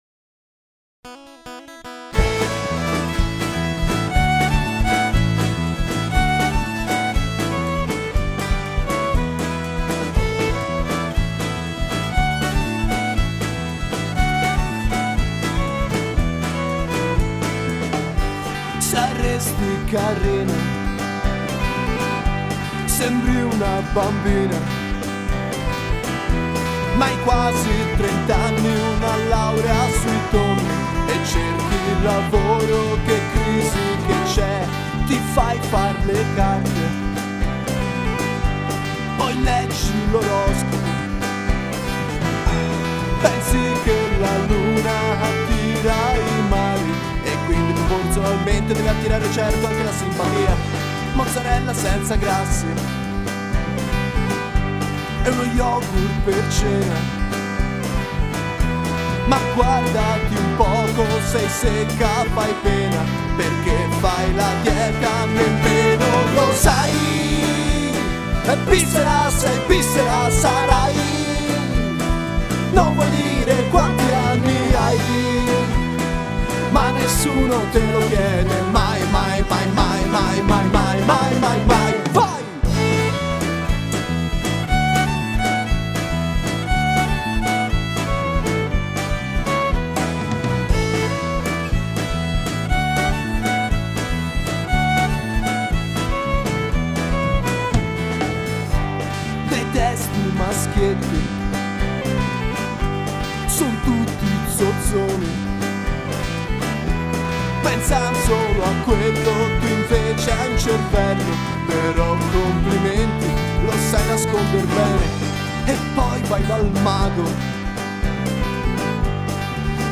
Country Demenziale